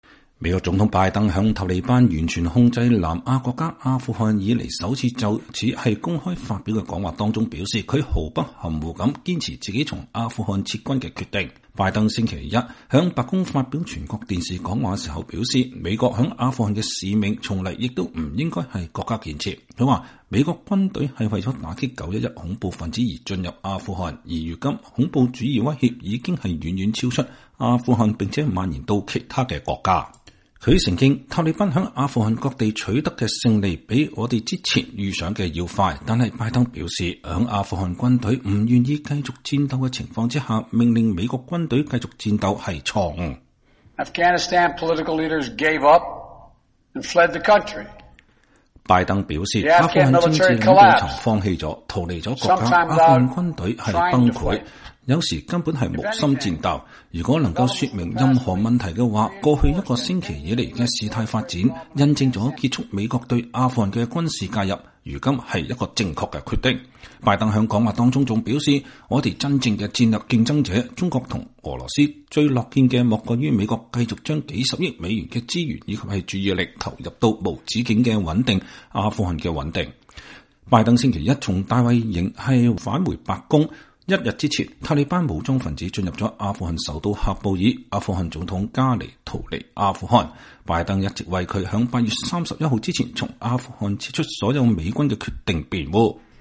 2021年8月16日,拜登總統在白宮東廳就阿富汗問題發表講話。